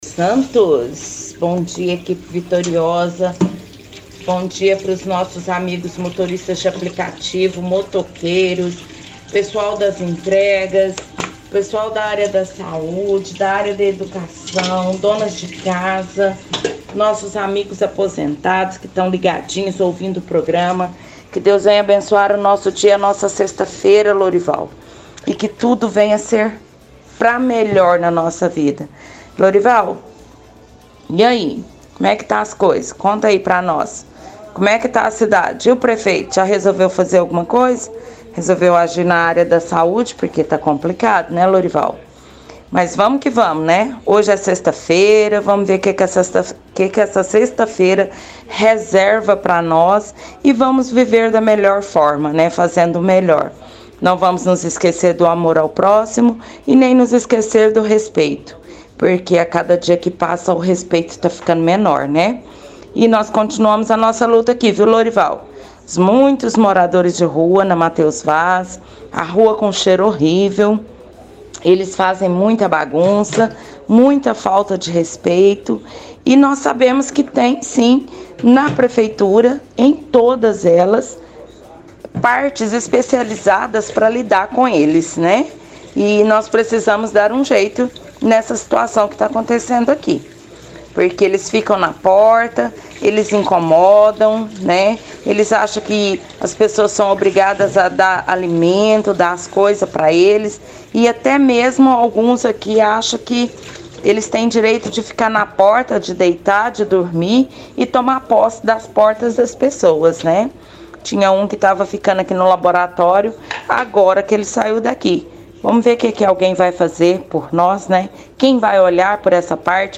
– Ouvinte do bairro Luizote reclama do sistema de saúde pública.